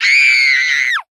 Звуки крика женщины
Юная девица громко кричит